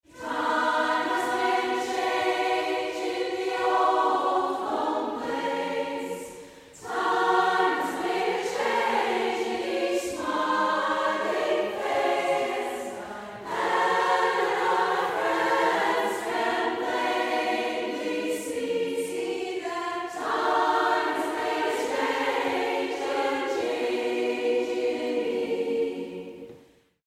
A gospel meditation on aging and change.
SSAA a cappella